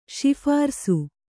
♪ śiphārsi